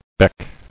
[beck]